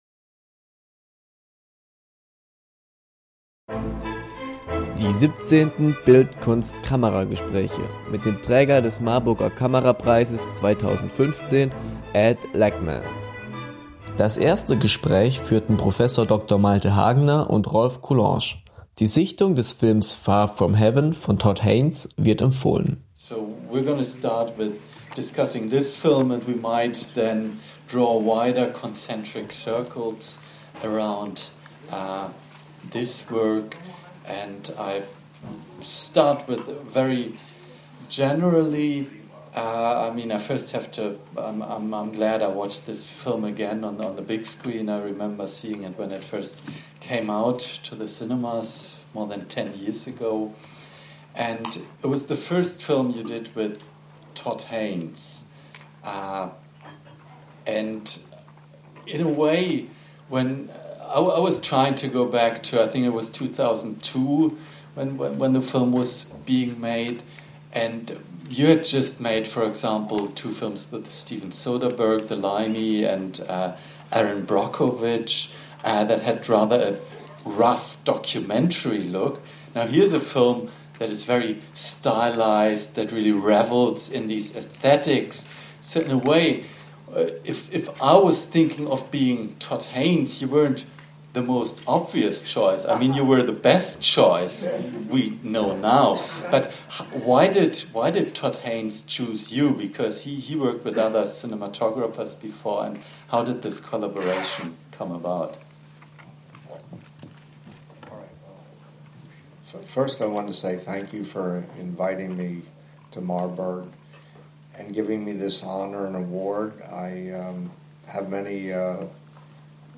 Werkstattgespräch Ed Lachmann Teil 1 .